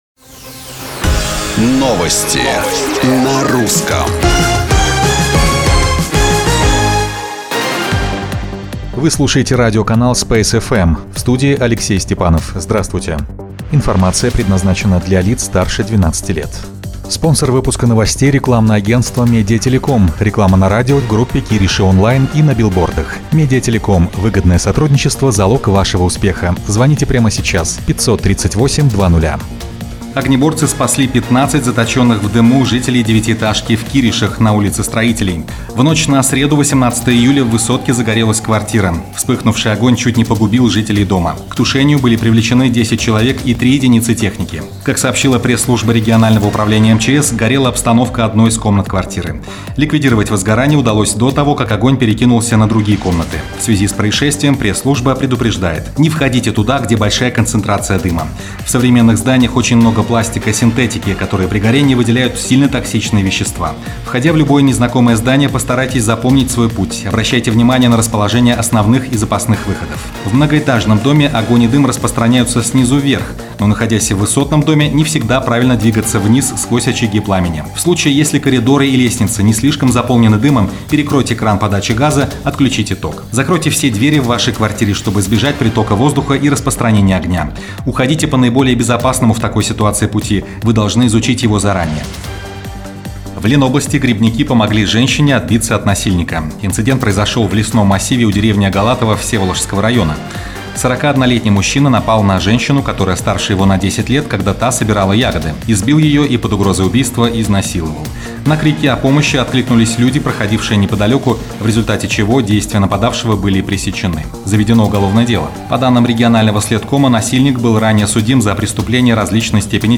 Новости Space FM 20.07.2018